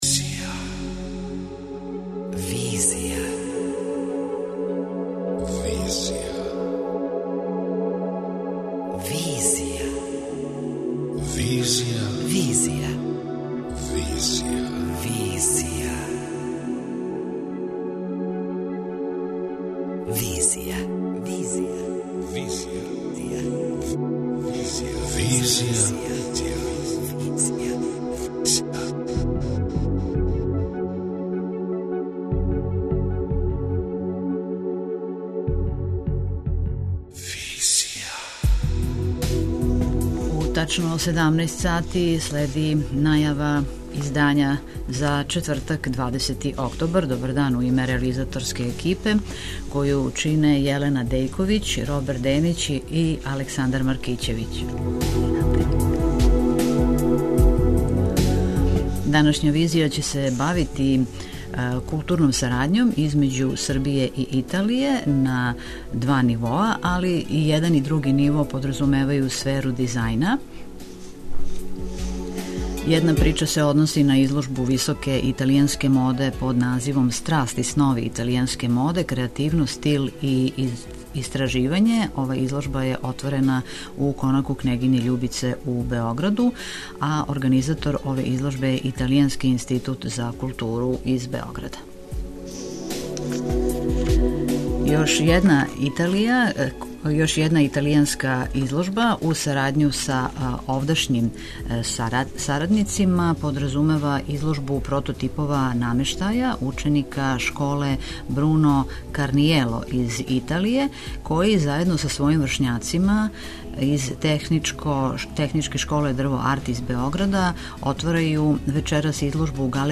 преузми : 26.91 MB Визија Autor: Београд 202 Социо-културолошки магазин, који прати савремене друштвене феномене.